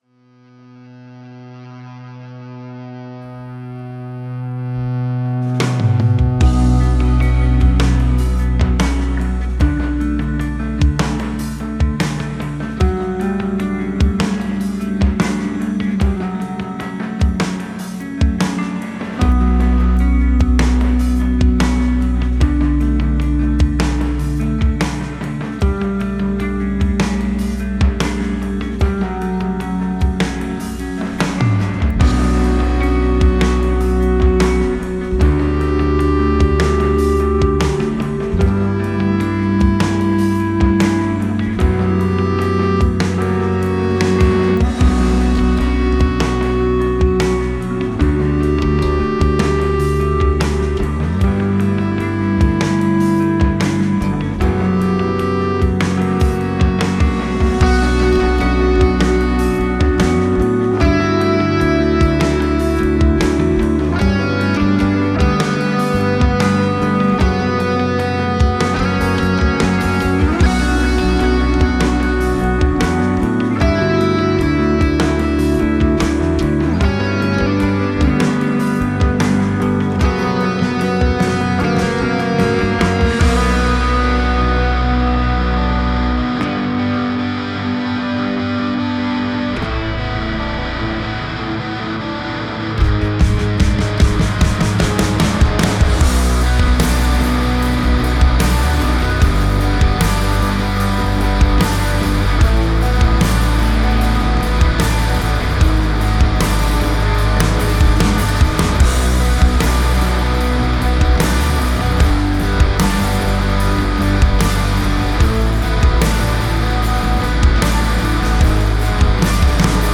blending heavy riffs with atmospheric soundscapes.